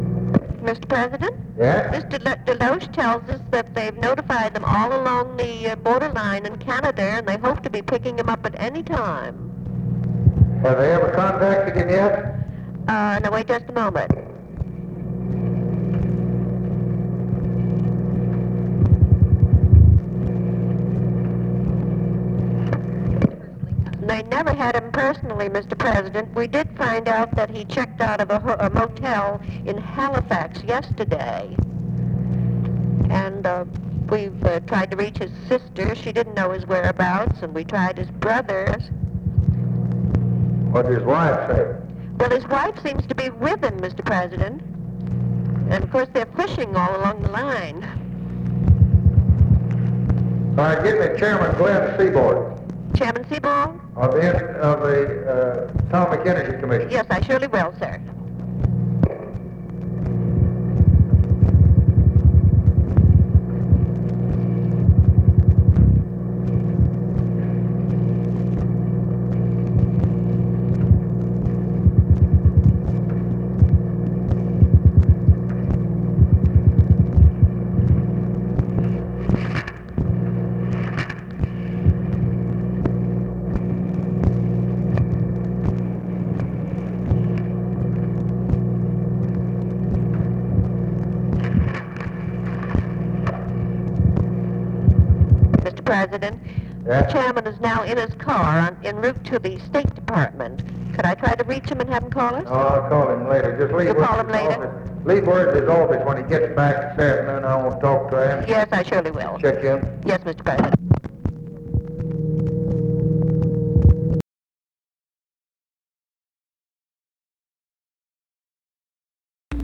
Conversation with TELEPHONE OPERATOR, July 15, 1964